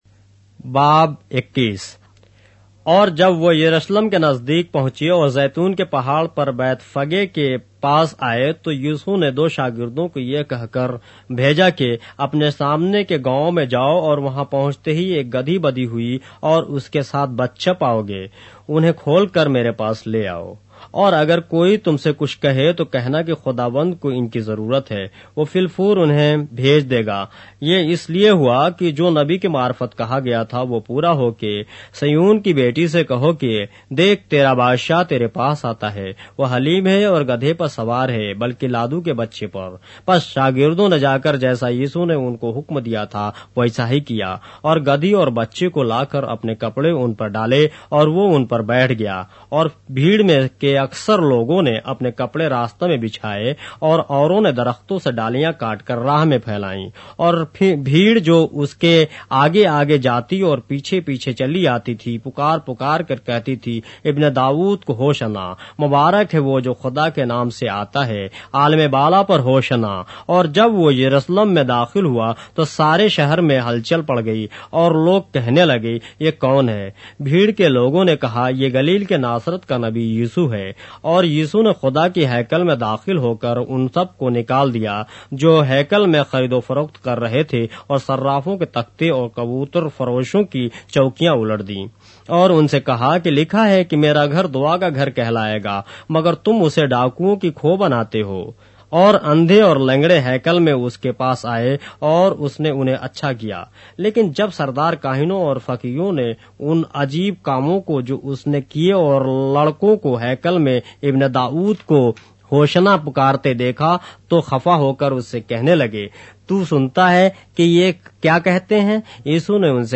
اردو بائبل کے باب - آڈیو روایت کے ساتھ - Matthew, chapter 21 of the Holy Bible in Urdu